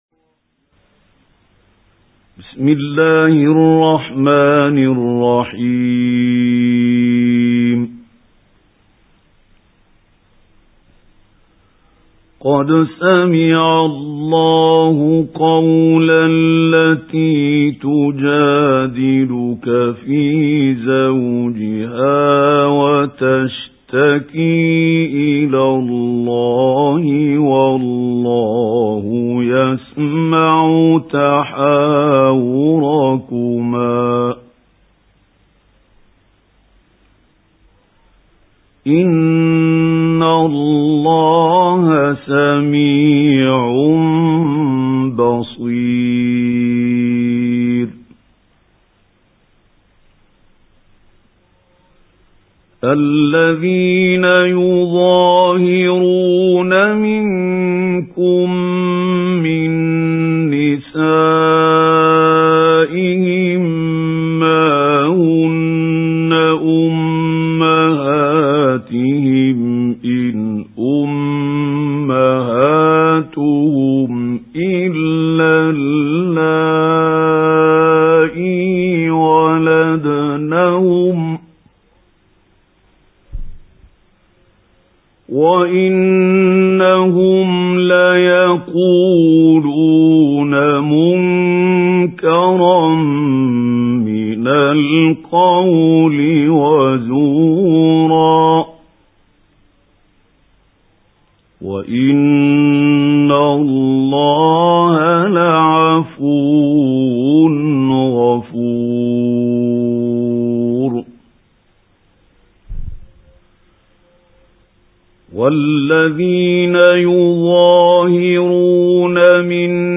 سُورَةُ المُجَادلَةِ بصوت الشيخ محمود خليل الحصري